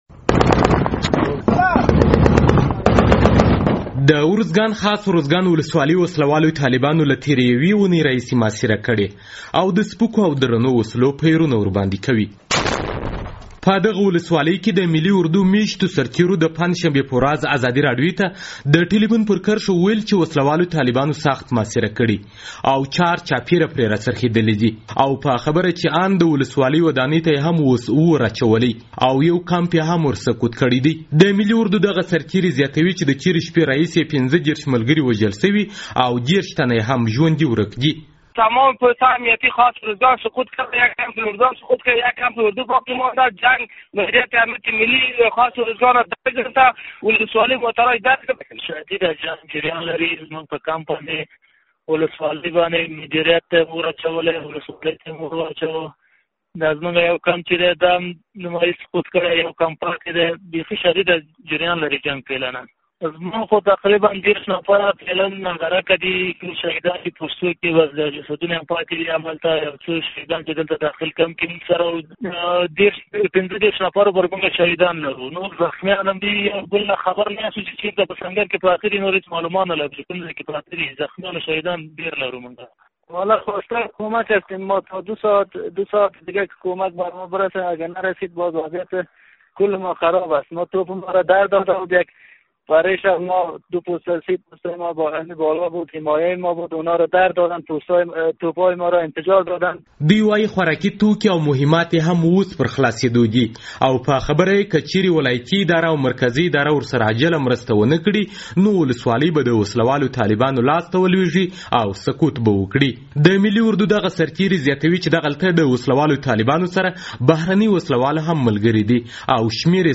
د ارزګان ولایت په خاص ارزګان ولسوالۍ کې د ملي اردو یو شمېر سرتېرو د پنجشنبې په ورځ له یادې ولسوالۍ څخه د ټیلیفون پر کرښه ازادي راډيو ته وویل چې وسله‌والو طالبانو سخت محاصره کړي او که اضافي سرتېري ورونه‌رسېږي ولسوالۍ به سقوط وکړي.
راپور